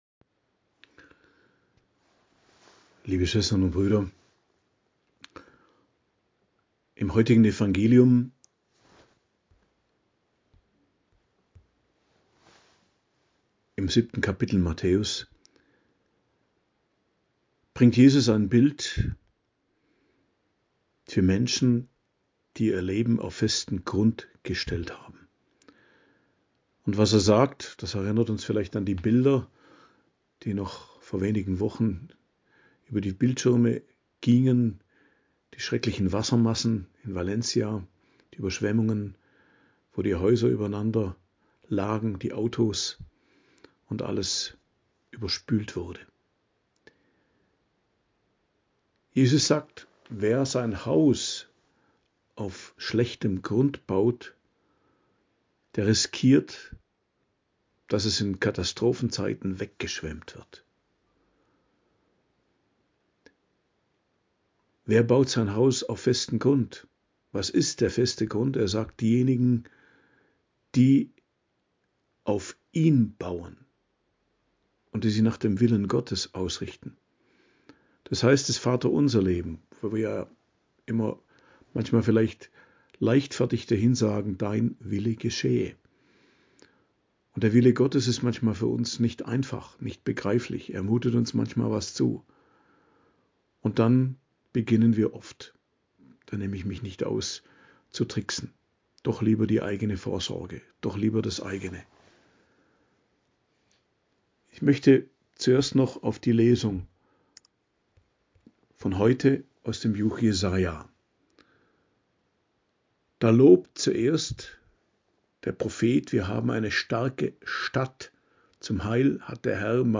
Predigt am Donnerstag der 1. Woche im Advent, 5.12.2024